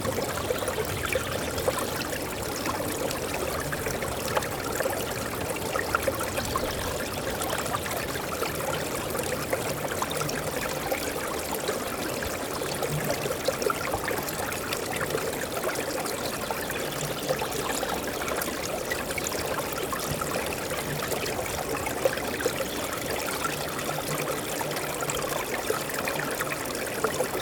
Aufnahmen von der Filsquelle
Bachrauschen Filsquelle 03.wav